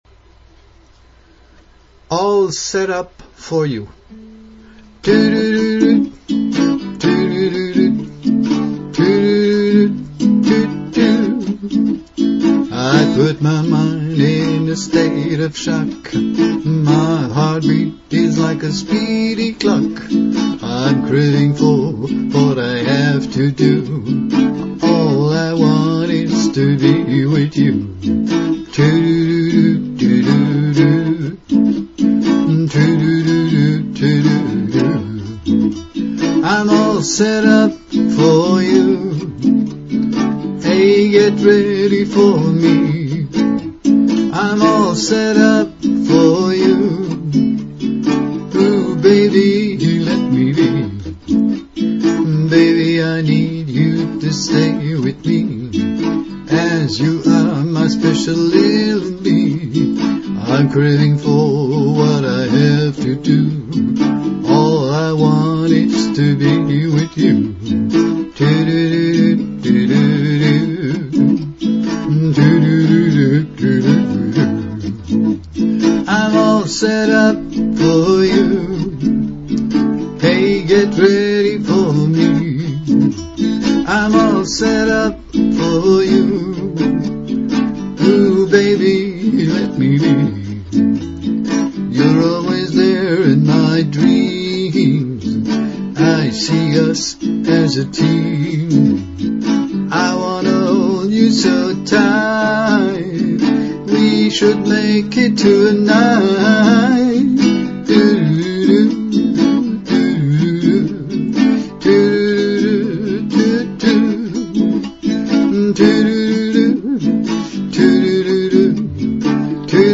Here is one of my songs.Rough demo: My voice + ukulele